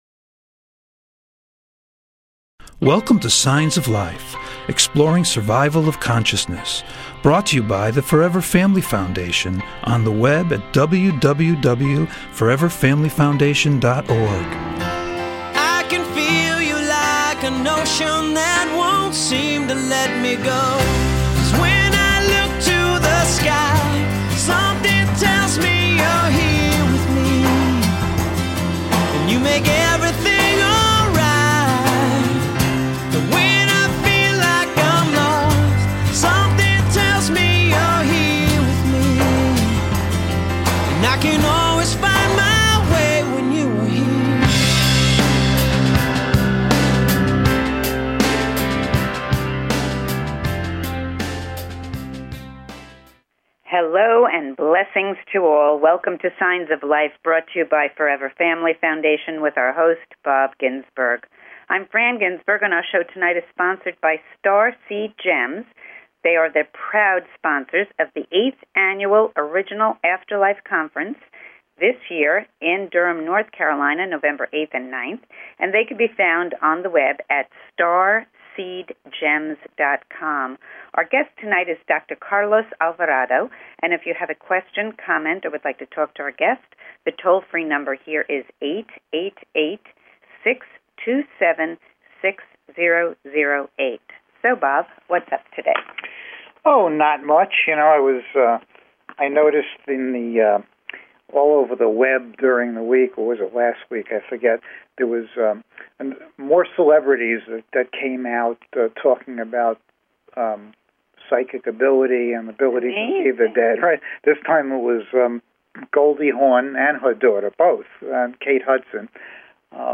Talk Show Episode, Audio Podcast, Signs_of_Life and Courtesy of BBS Radio on , show guests , about , categorized as
Call In or just listen to top Scientists, Mediums, and Researchers discuss their personal work in the field and answer your most perplexing questions.